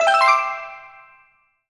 collect_item_07.wav